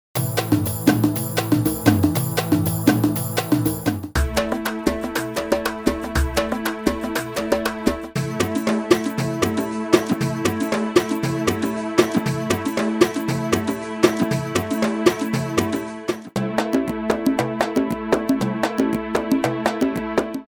این پکیج بی‌نظیر شامل ۱۸۷ فایل حرفه‌ای وان‌شات از جمله Kick، Snare، Clap، Rim، Hihat، Shaker، Percussion و Fill بوده که دقیقا برای تولید ترک‌های هیت و ترند طراحی شده است.
۴ پروژه لوپ کامل ساخته شده با این صداها به صورت رایگان در کنار این پکیج به شما تقدیم می گردد!
دموی صوتی لوپ ها: